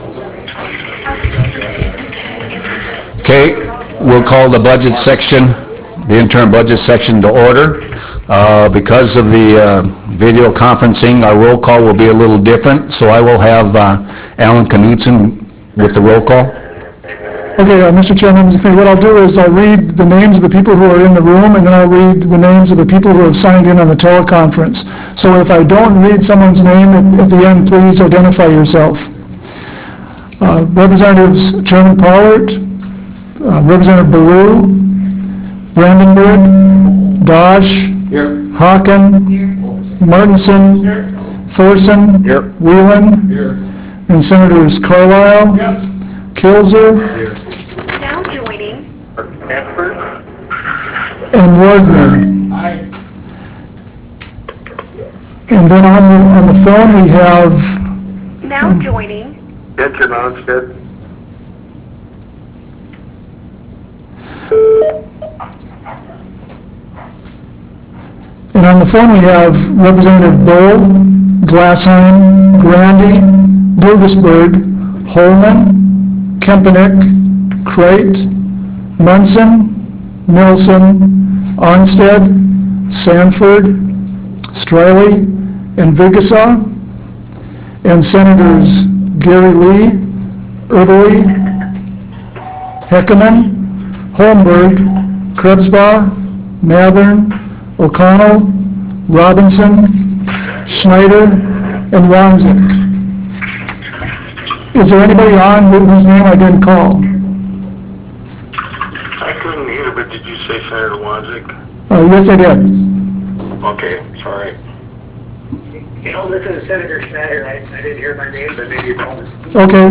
Roughrider Room State Capitol Bismarck, ND United States
Meeting Audio